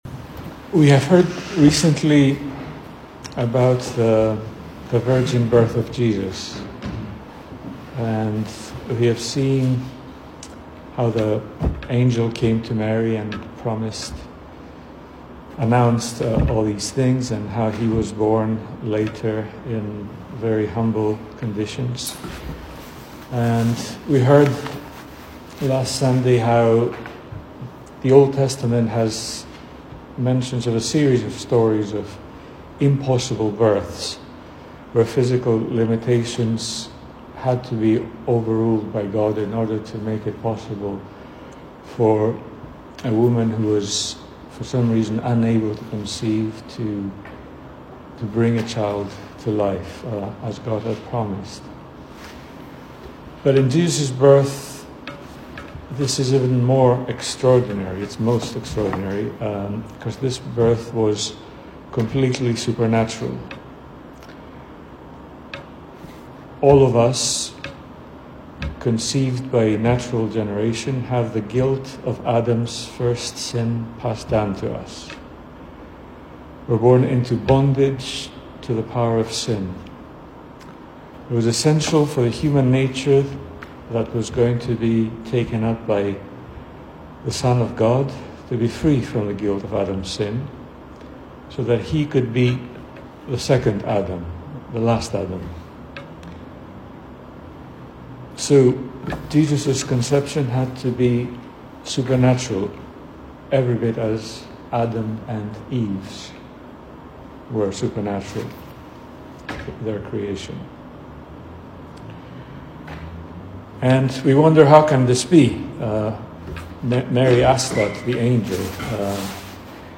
Service Type: Weekday Evening
Series: Single Sermons